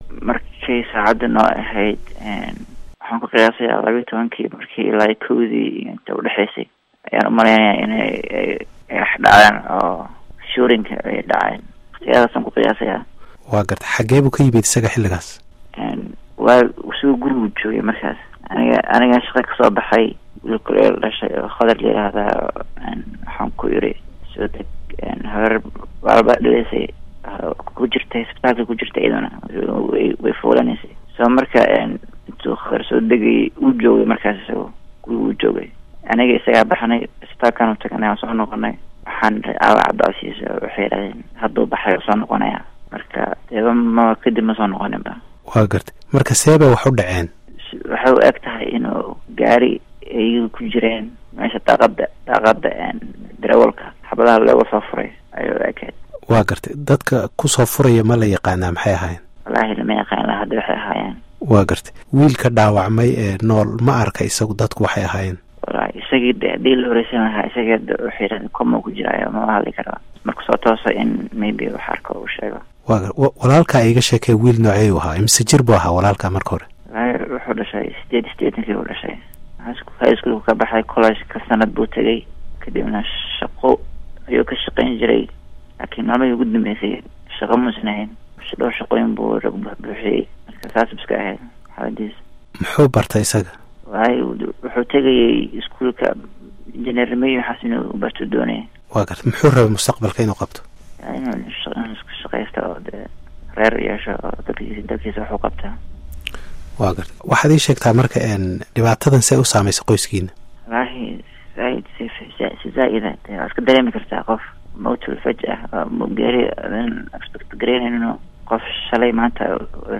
Waraysiga